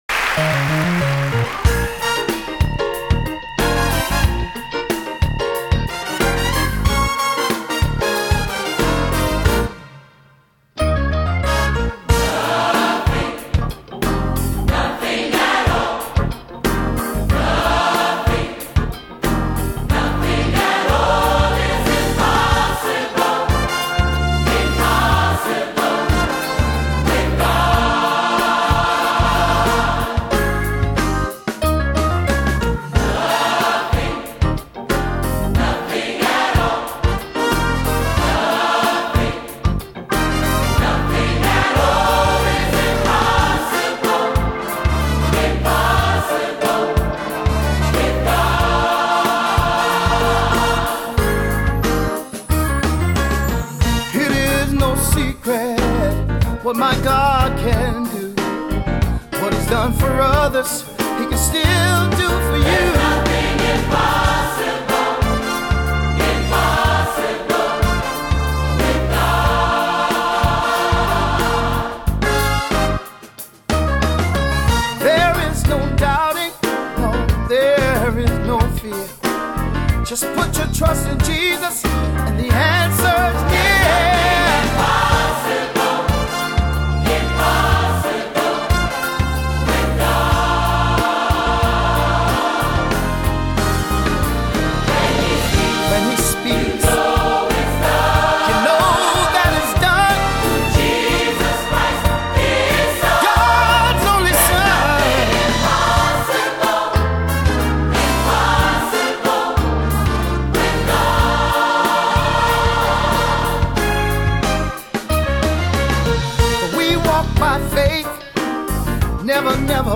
Anthem for this Sunday